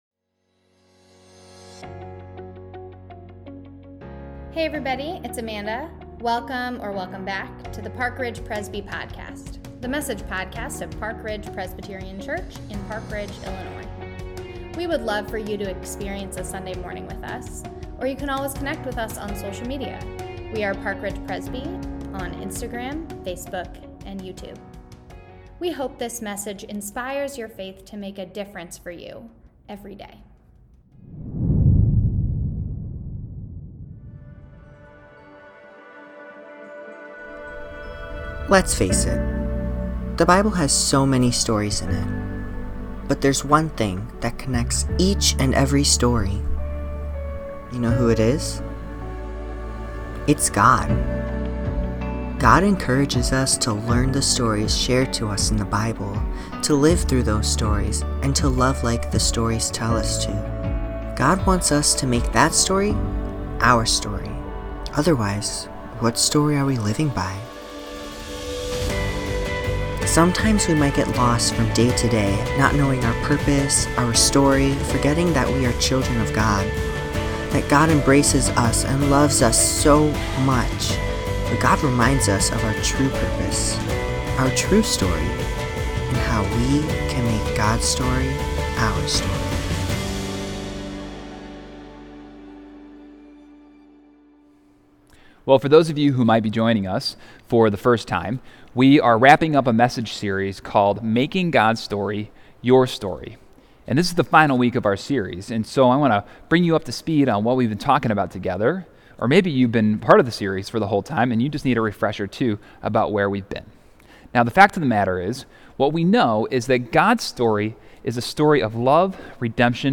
Sermon-9-5-21.mp3